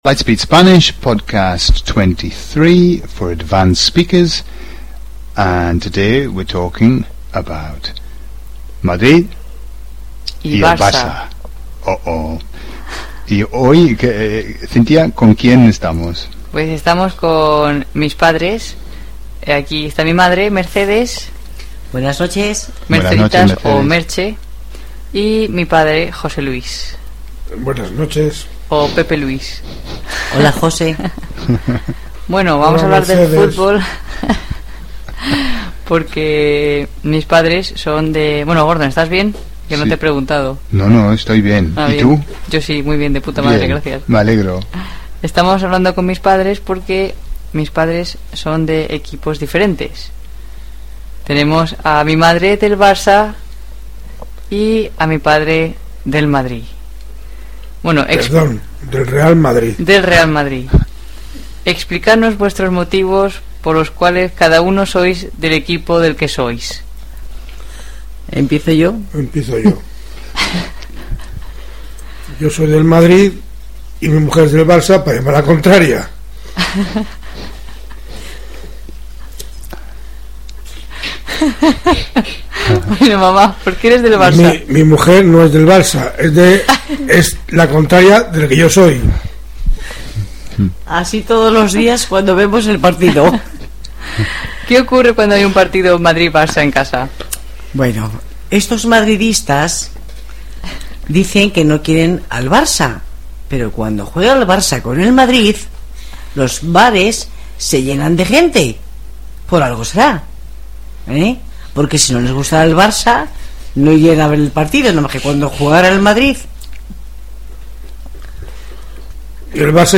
There has existed a rivalry with Madrid vs Barcelona for many years. Listen to a fun exchange between un madridista (Madrid) and una culé (Barcelona).